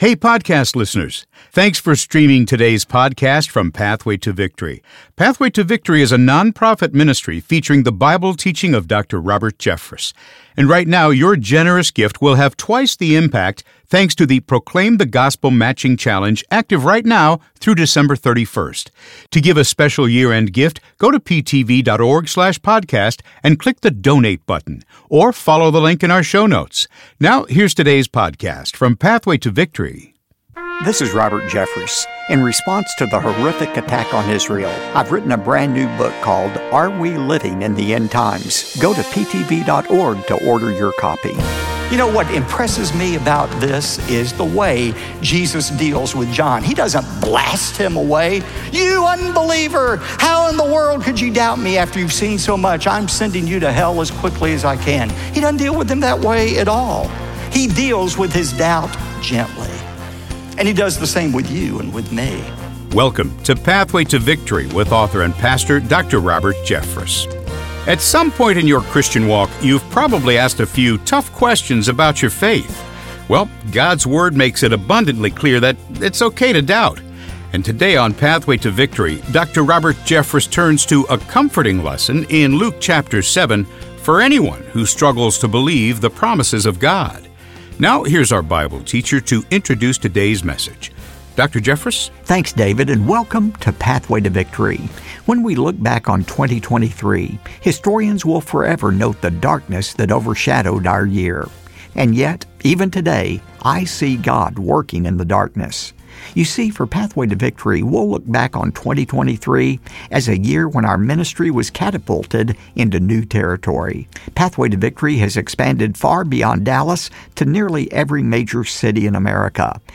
Dr. Robert Jeffress shares a comforting lesson in Luke chapter 7 for those who struggle to believe the promises of God, emphasizing that it's okay to doubt and that God deals with doubt gently.